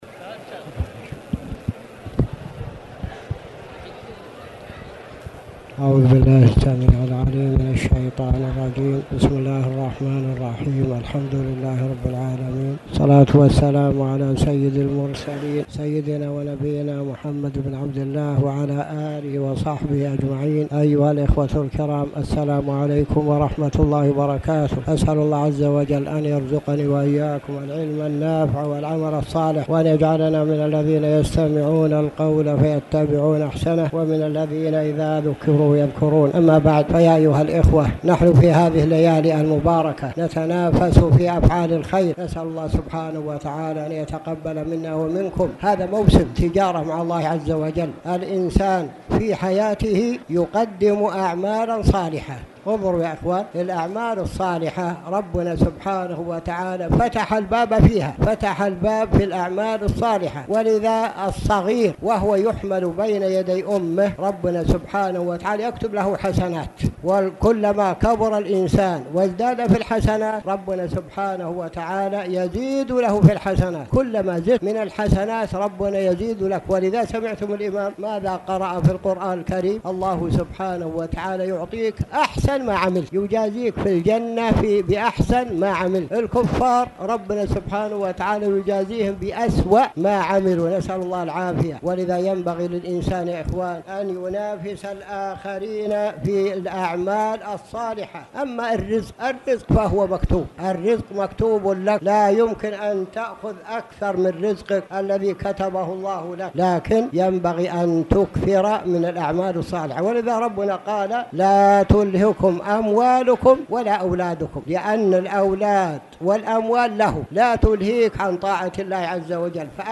تاريخ النشر ٢٣ رمضان ١٤٣٨ هـ المكان: المسجد الحرام الشيخ